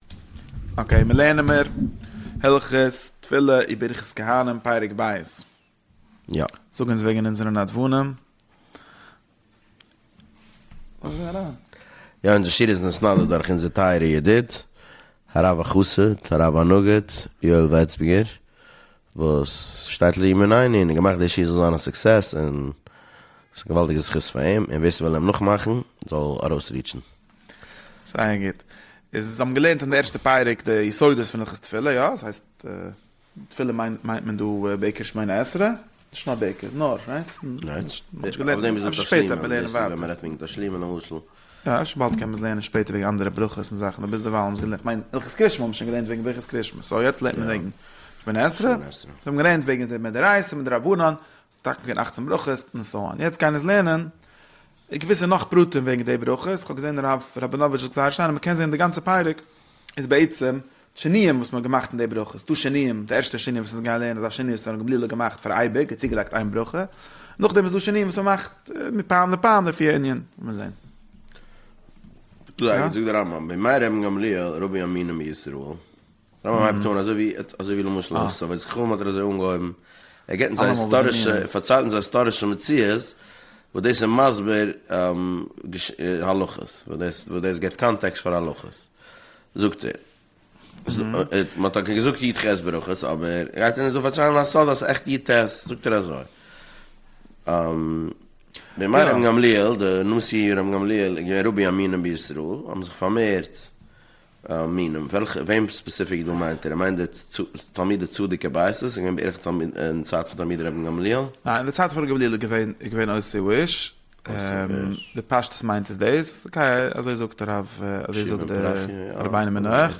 שיעורים על הרמב"ם פרק אחד ליום